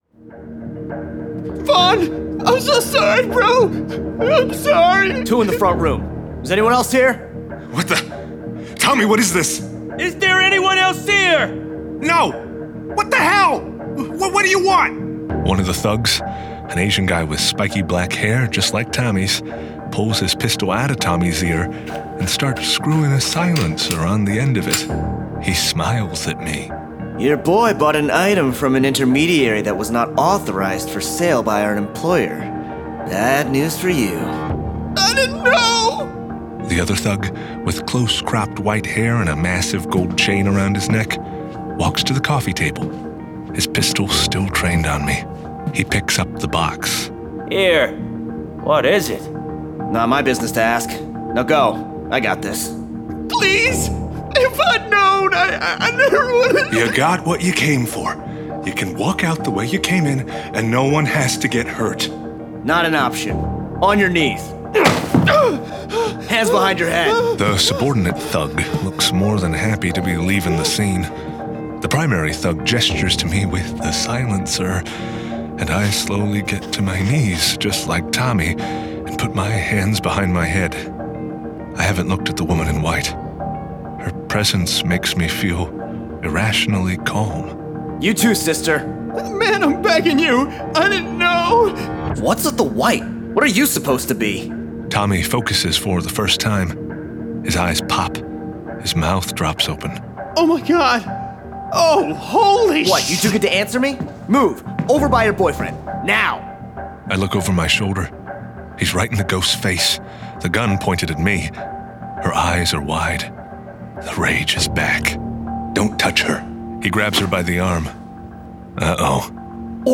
Full Cast. Cinematic Music. Sound Effects.
[Dramatized Adaptation]
Adapted from the issues and produced with a full cast of actors, immersive sound effects and cinematic music.